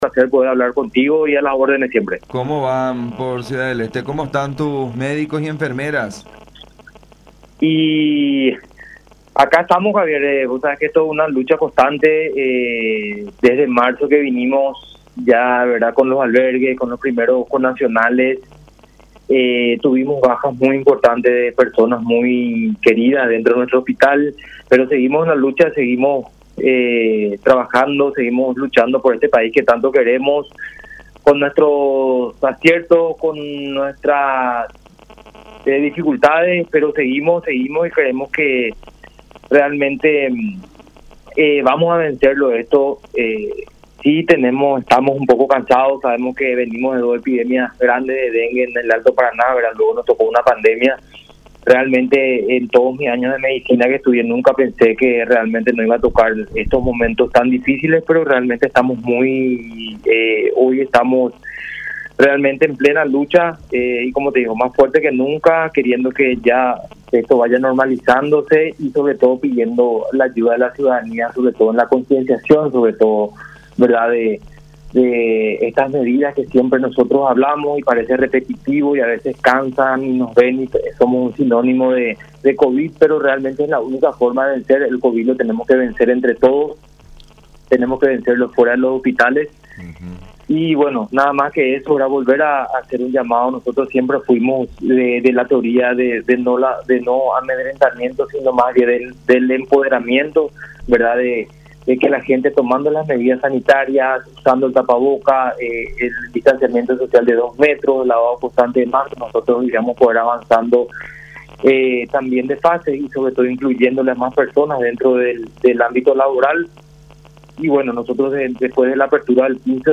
en conversación con La Unión